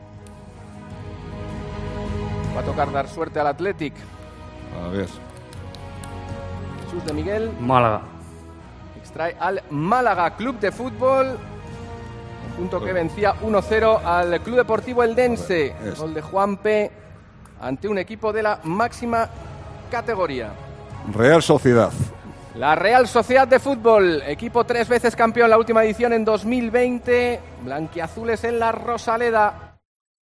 Así sonó el sorteo de Copa para el Málaga hoy en Madrid